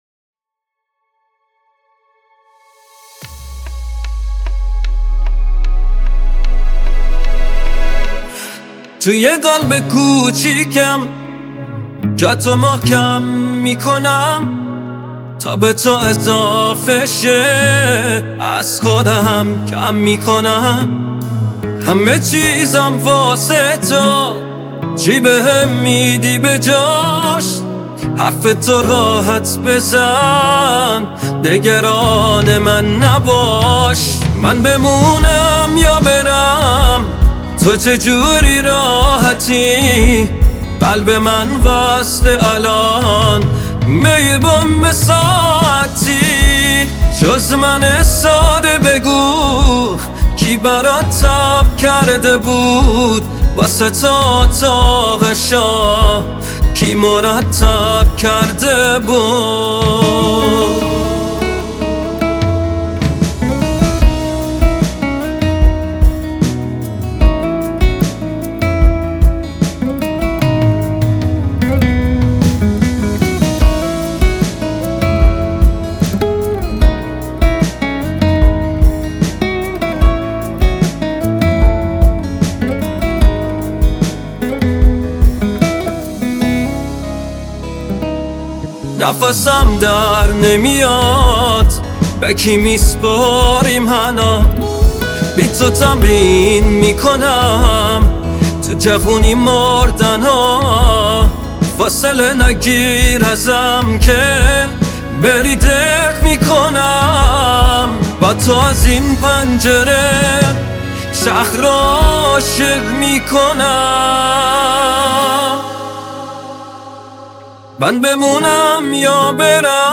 پاپ